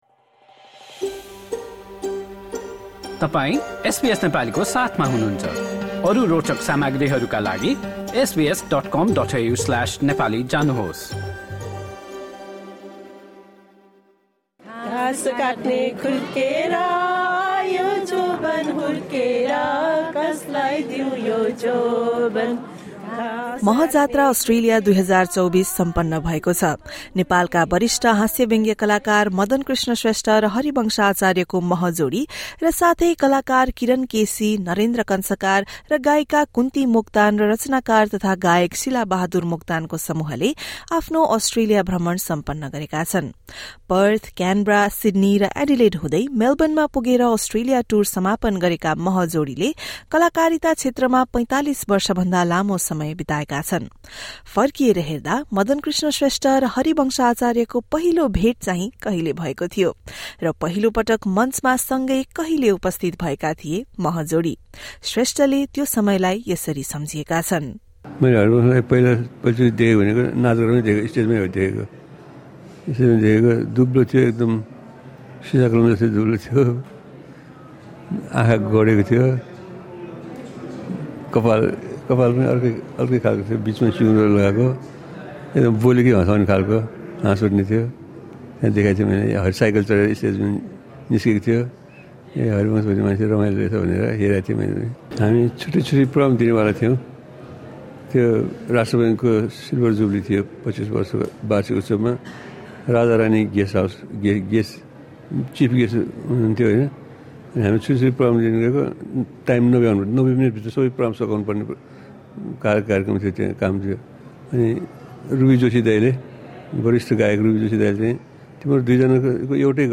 नेपालका चर्चित हास्य व्यङ्ग्य कलाकार मदनकृष्ण श्रेष्ठ र हरिवंश आचार्यको जोडी सहितको टोलीले अस्ट्रेलियामा मह जात्रा सन् २०२४ सम्पन्न गरेका छन्। भ्रमणबारे यस विस्तृत रिपोर्ट सुन्नुहोस्।